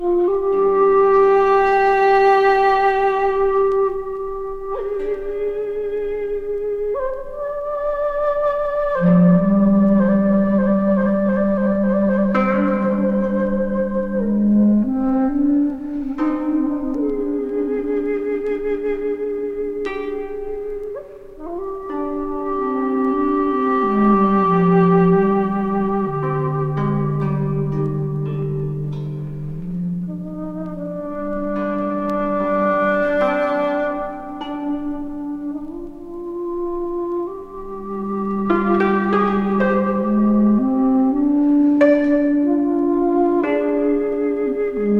三者三様の日本的な美しい音色と響きがどこかへ誘ってくれる。
Jazz, New Age, Abstract　Germany　12inchレコード　33rpm　Stereo